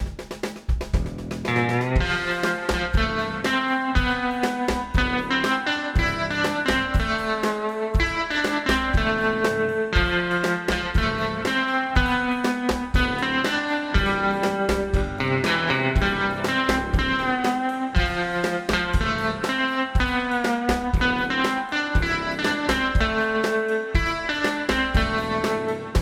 Zwei Amps (wahrscheinlich british und american) sowie ein Federhall bilden die Grundlagen der Klangbildung.
desolate-guitars-4.mp3